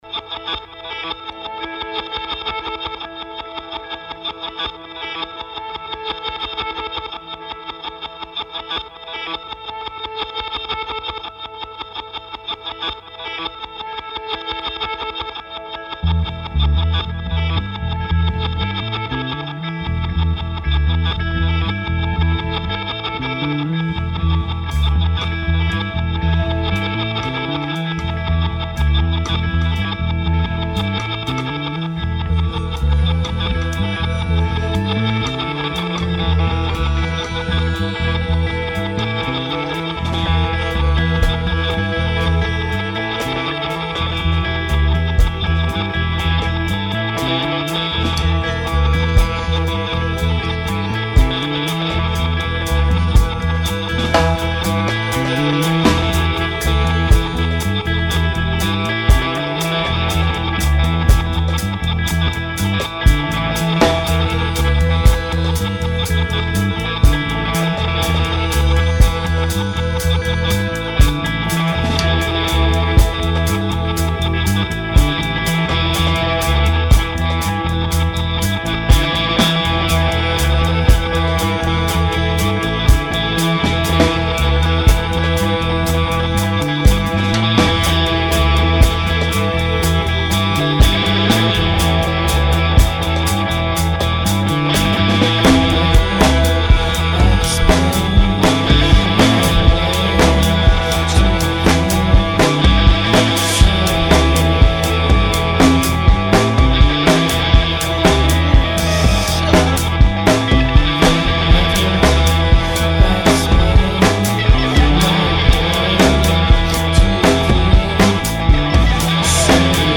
guitar/loops/ebow/voice
drum/loops
bass
mobile studio in Firenze between june/november 2005.